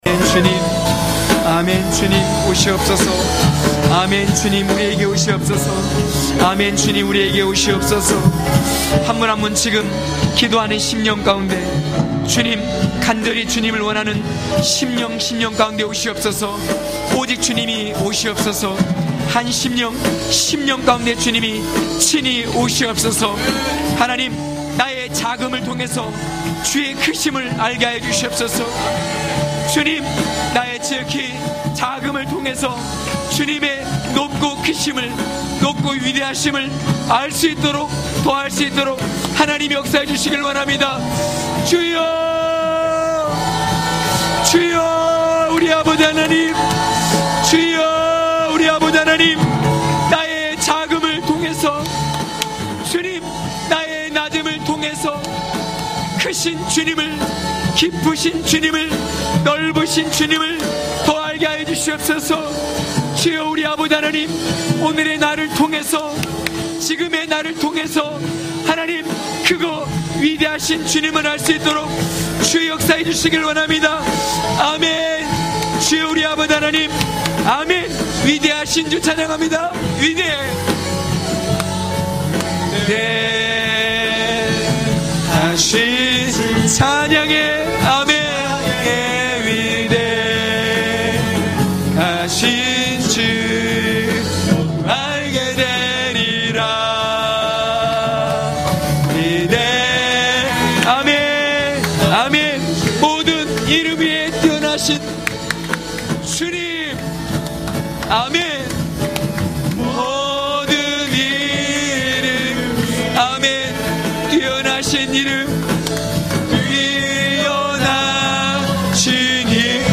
강해설교 - 16.말씀이 들려올 때..(느9장1~8절).mp3